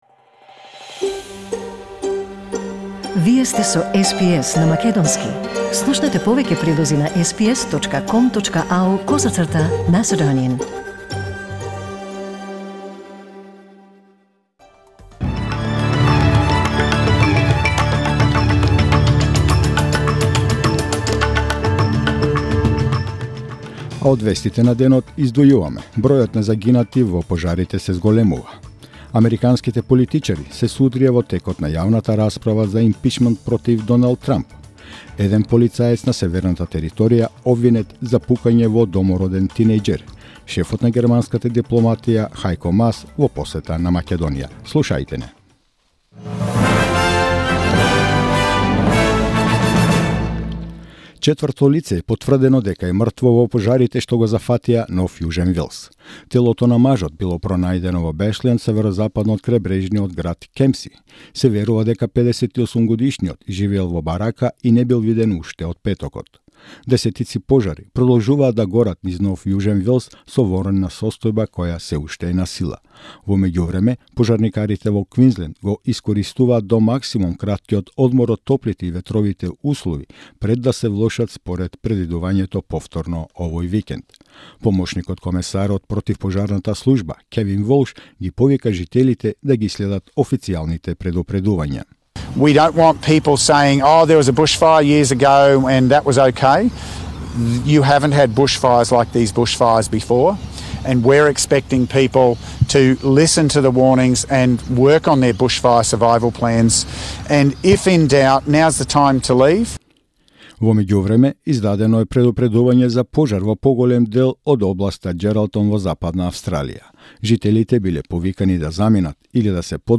SBS News in Macedonian 14th November 2019